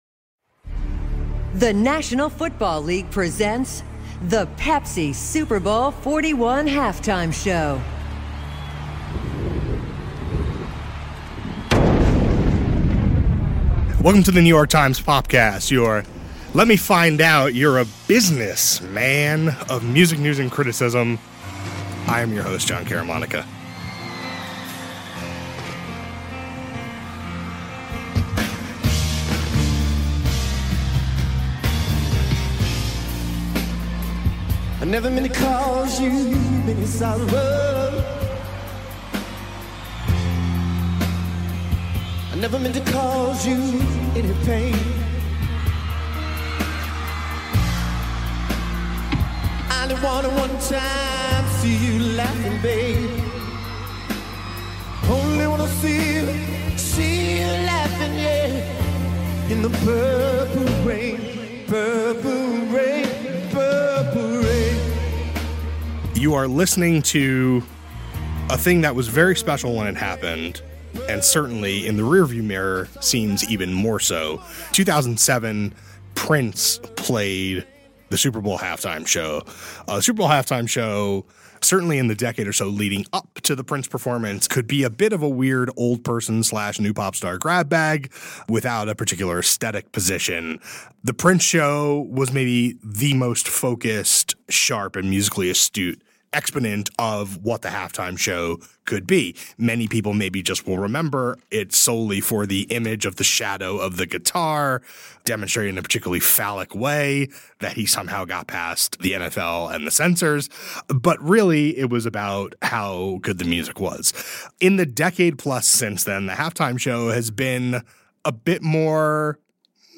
A conversation about how its meaning has evolved over the last two decades.